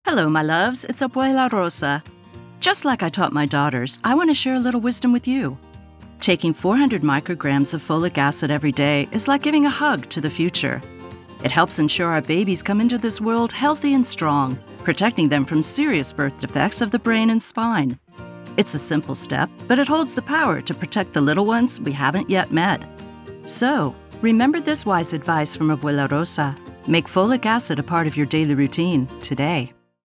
Use this public service announcement (PSA) in English and Spanish to help raise awareness among Hispanic/Latina women about the importance of getting 400 mcg folic acid daily.